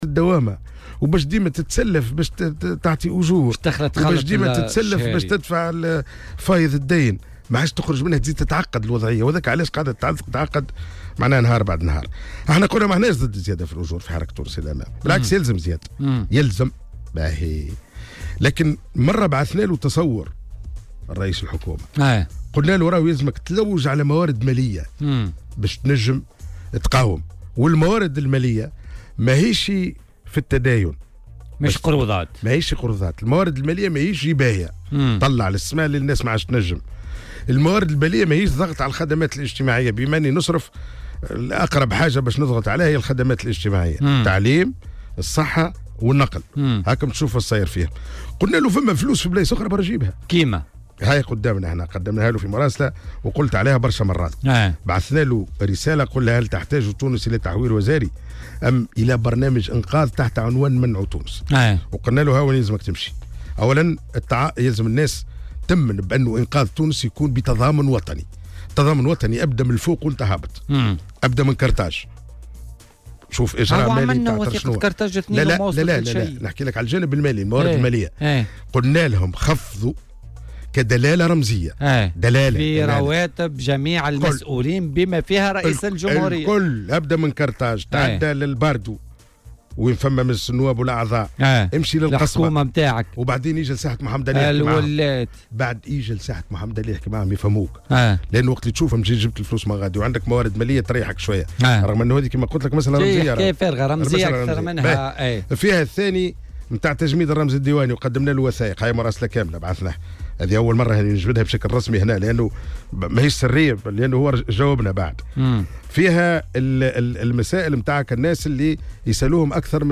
وتابع ضيف "بوليتيكا" على "الجوهرة أف أم"، أنه أرسل تصوّرا لرئيس الحكومة وصفه برنامج انقاذ.